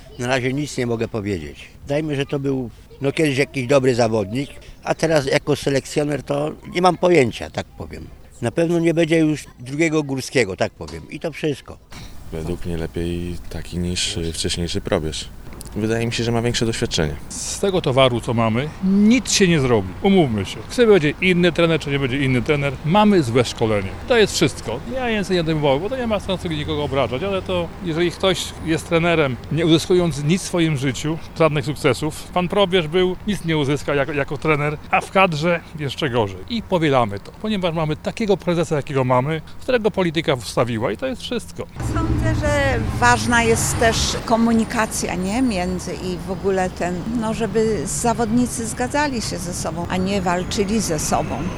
O ocenę poprosiliśmy wrocławian.
urban-sonda.wav